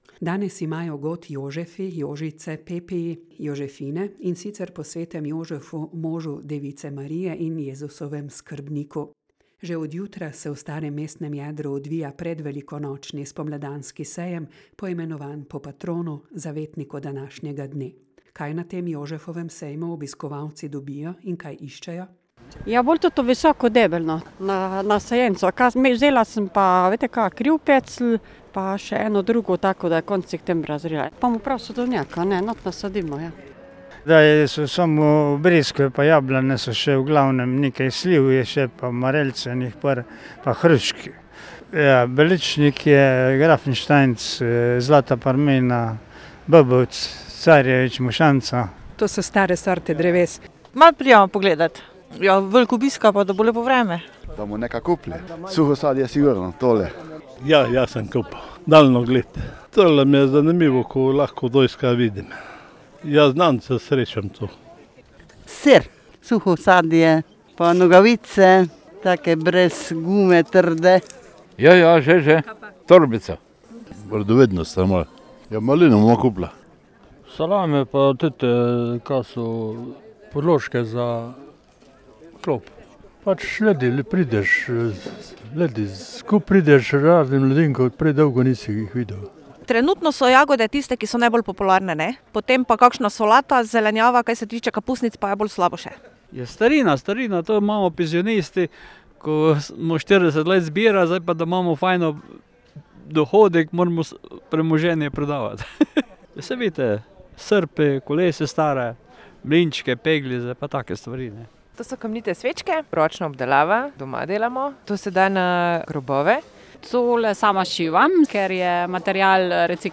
Že od jutra se v starem mestnem jedru Slovenj Gradca odvija predvelikonočni, spomladanski sejem, Jožefov sejem. Kaj tam obiskovalci dobijo in kaj iščejo?